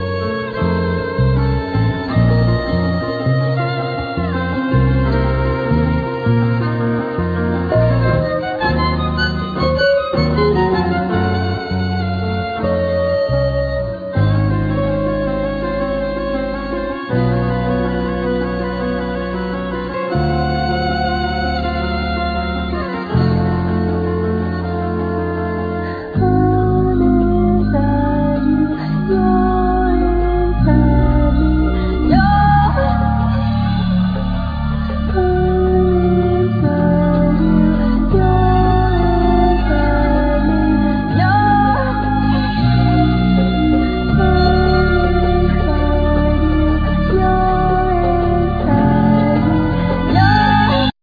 Violin,Vocals
Keyboards,Backing vocals
Drums,Percussions
Guitar,Vocals
Bass